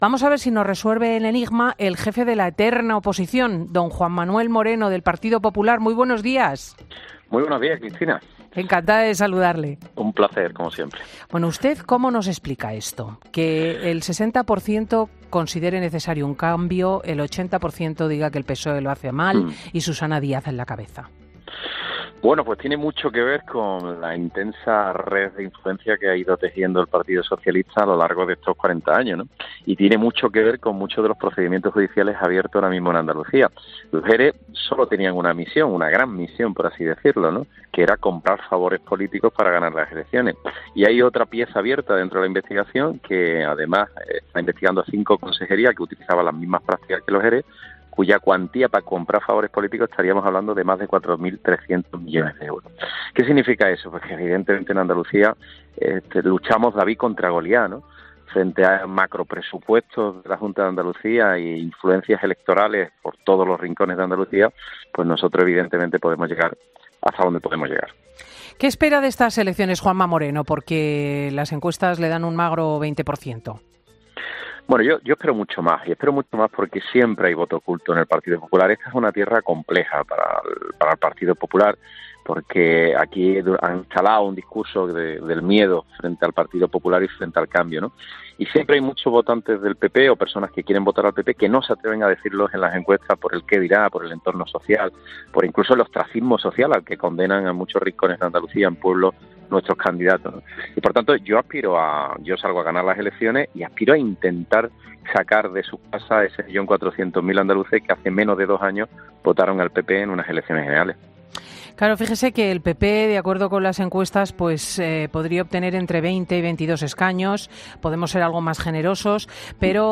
ESCUCHA LA ENTREVISTA COMPLETA | Juanma Moreno Bonilla en 'Fin de Semana'